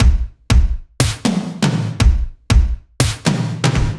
Index of /musicradar/french-house-chillout-samples/120bpm/Beats
FHC_BeatB_120-03_KikSnrTom.wav